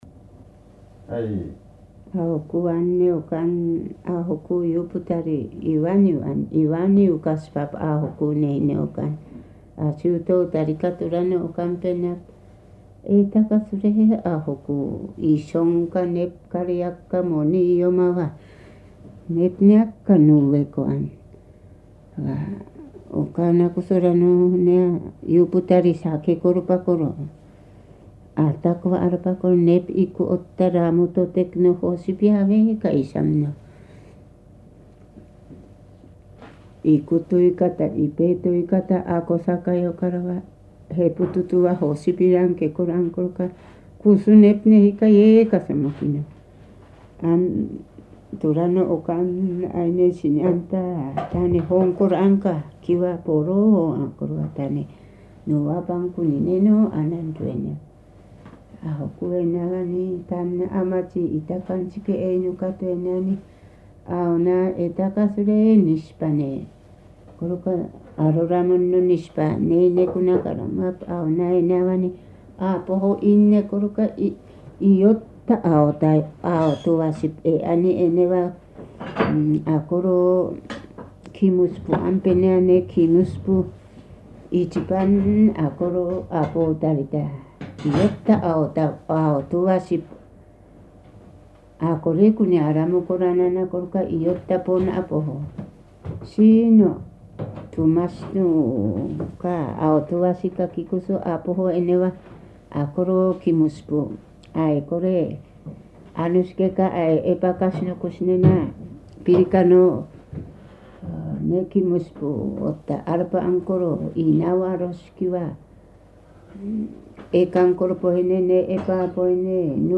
[19-5 散文説話 prose tales]【アイヌ語】23:22